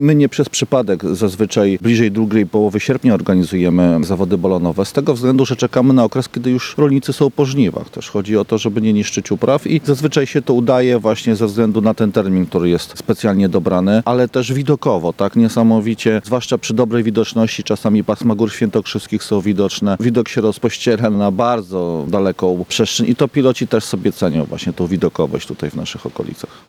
– Mamy idealne warunki terenowe do organizacji tego typu imprez – chodzi między innymi o miejsca startów i lądowań – mówi burmistrz Nałęczowa, Wiesław Pardyka.